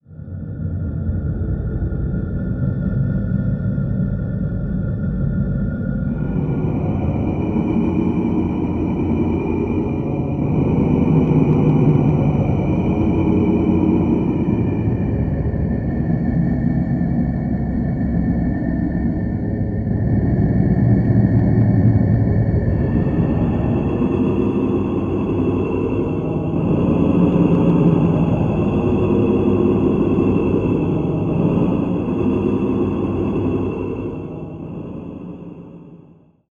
Звуки арктической атмосферы